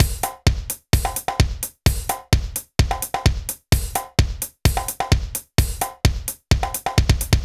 BAL Beat - Mix 5.wav